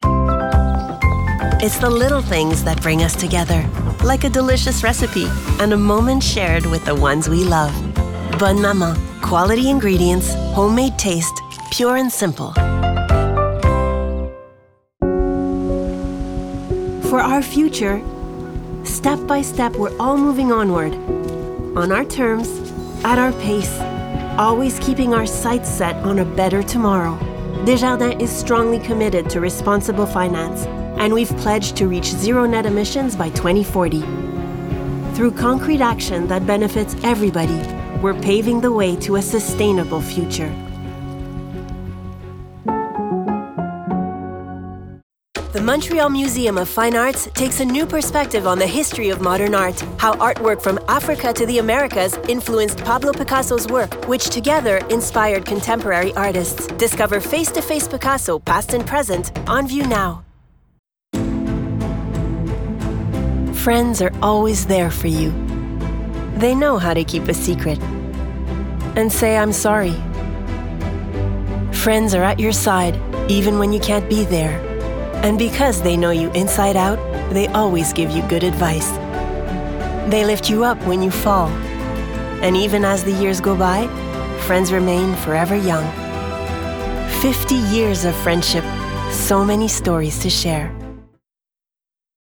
Publicités - EN